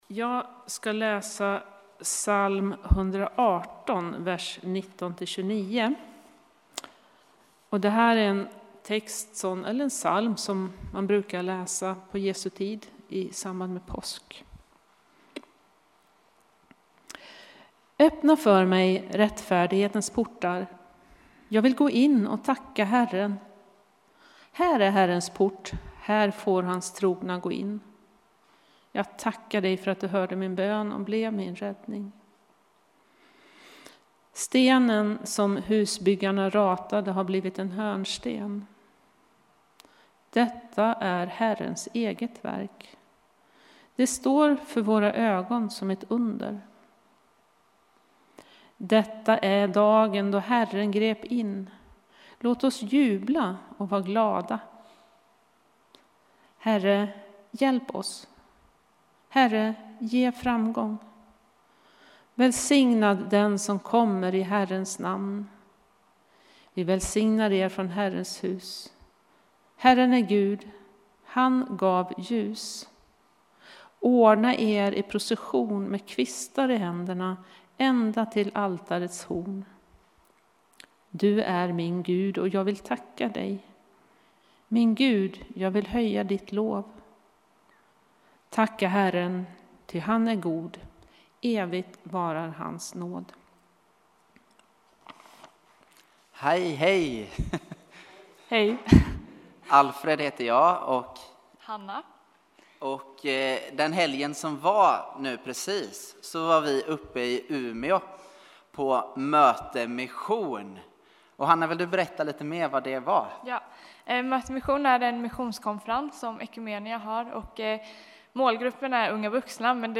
Palmsöndagen. Textläsning, predikanPsalm 118:19-29, Mark 11:1-11, Upp 3:20